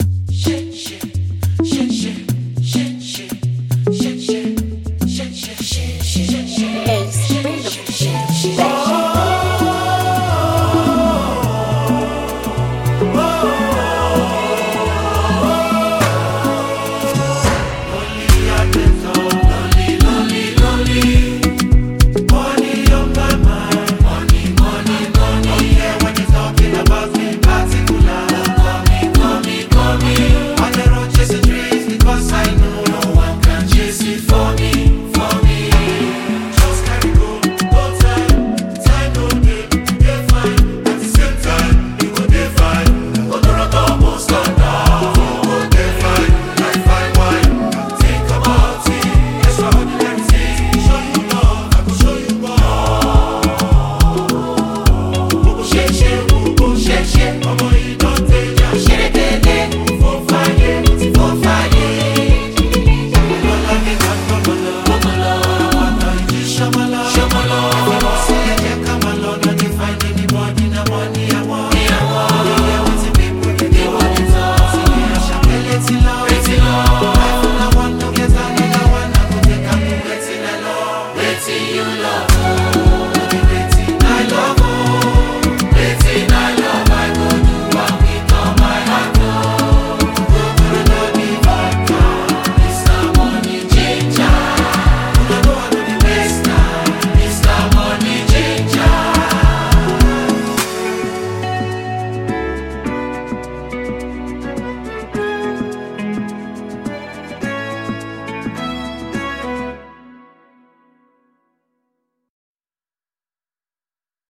Well renowned Nigerian comic group
thrilling new gbedu song